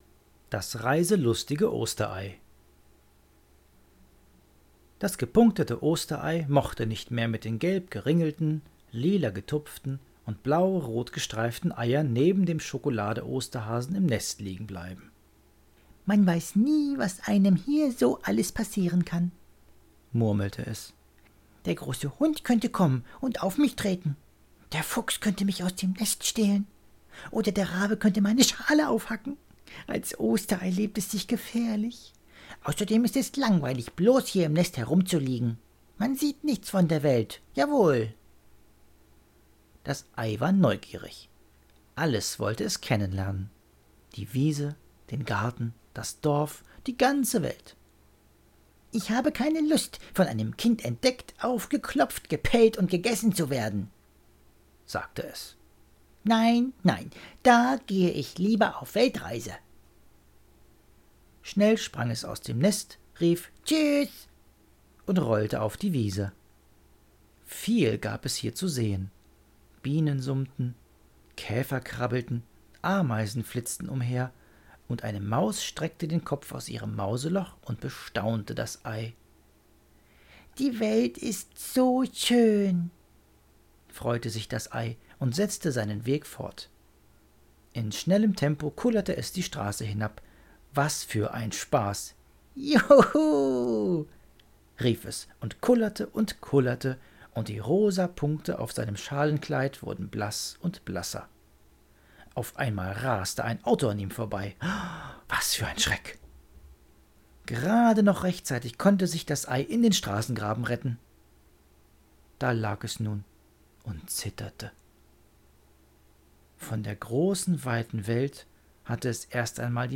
Ostereiermärchen – Einmal wurde es dem Osterei zu langweilig im Nest.